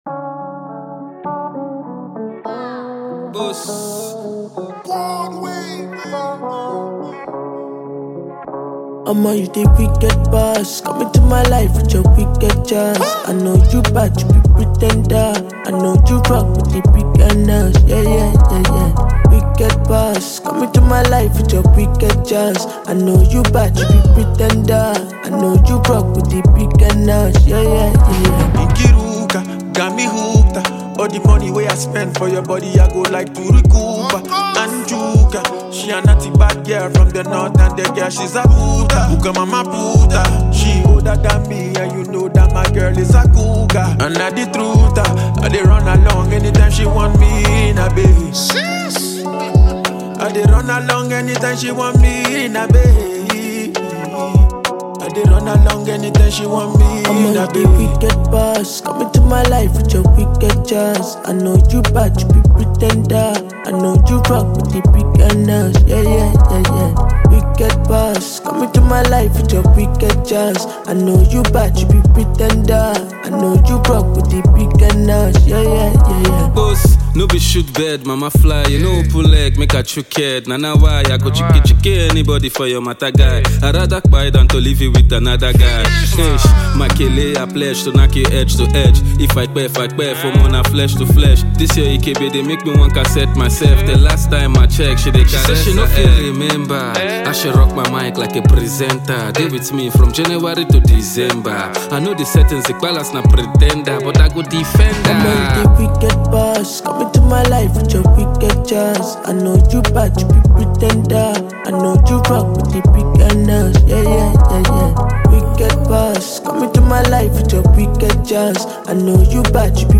Nigerian music duo
British-Nigerian rapper